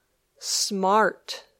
Haz clic en la palabra para escuchar el audio y repetir la pronunciación.